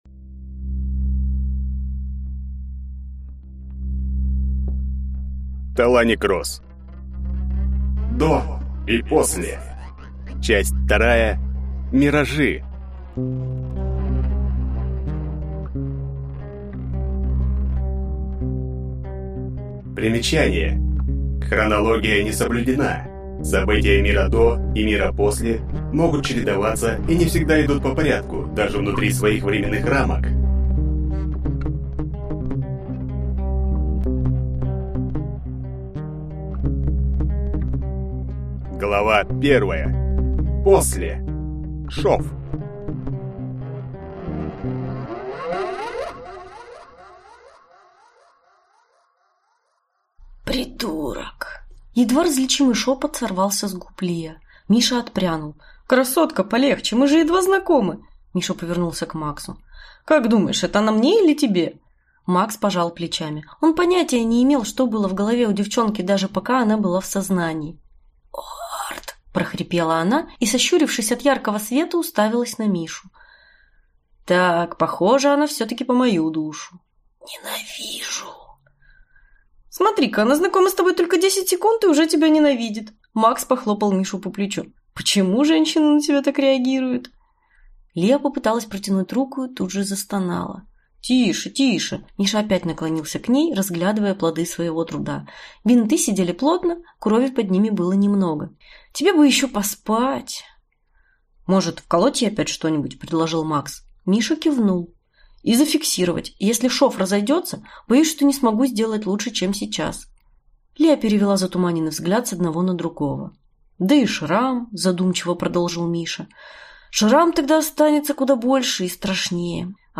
Аудиокнига До и После: Миражи | Библиотека аудиокниг
Прослушать и бесплатно скачать фрагмент аудиокниги